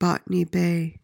PRONUNCIATION:
(BOT-uh-nee BAY)